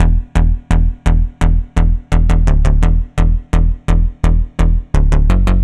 Index of /musicradar/80s-heat-samples/85bpm
AM_OB-Bass_85-E.wav